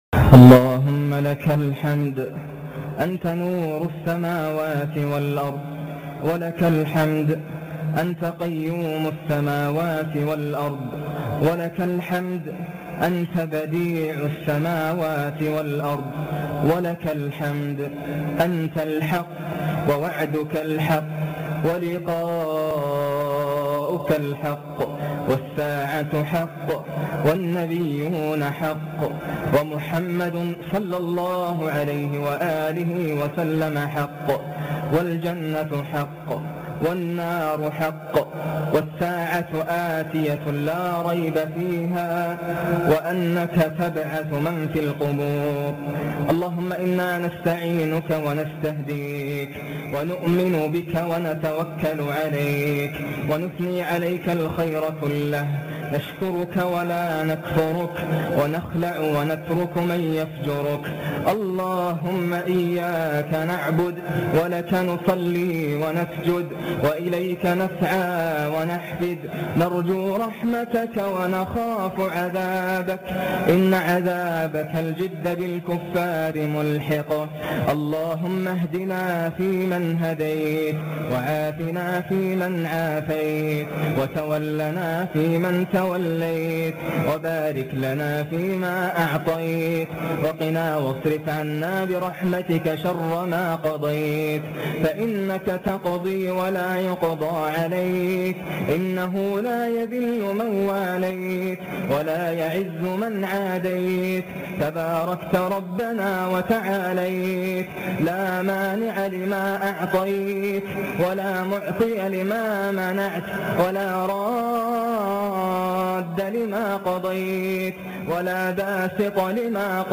حصري - دعاء معبر وخاشع نادر جدا للشيخ ناصر القطامي حفظه الله سمعي MP3